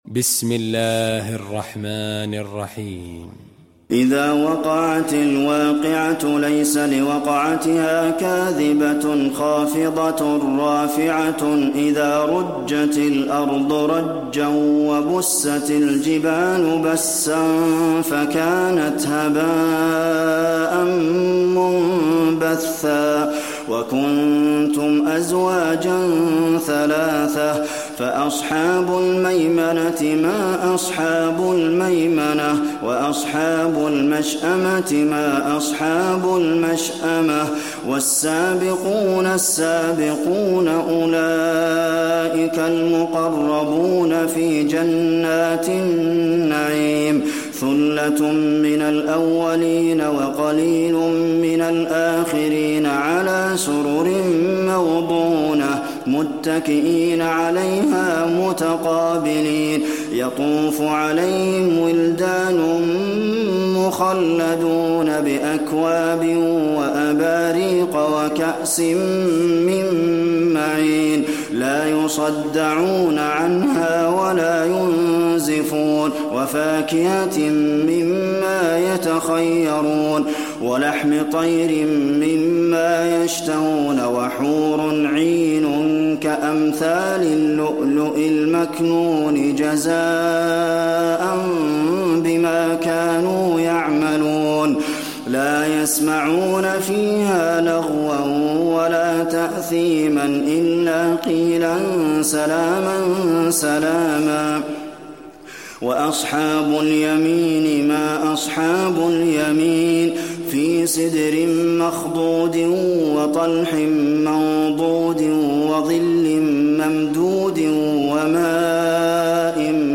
المكان: المسجد النبوي الواقعة